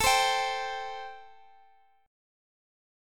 C5/A chord